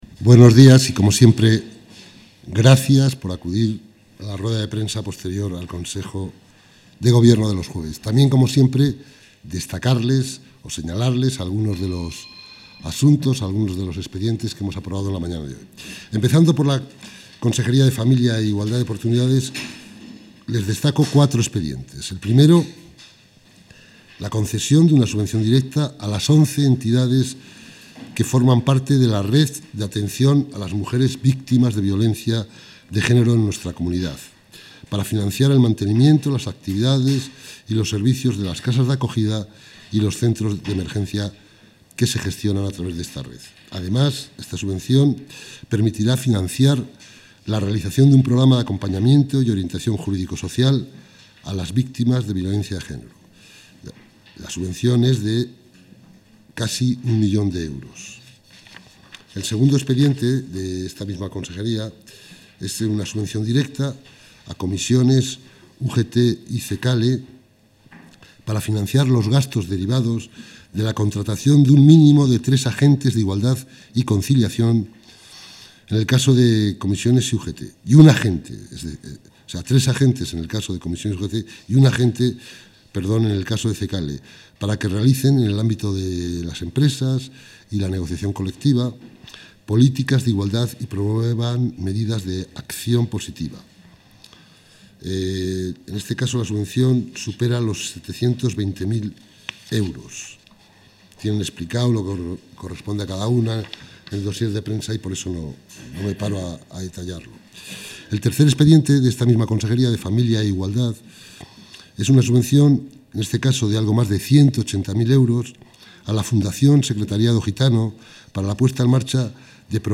Audio rueda de prensa.
Consejo de Gobierno del 2 de mayo de 2019.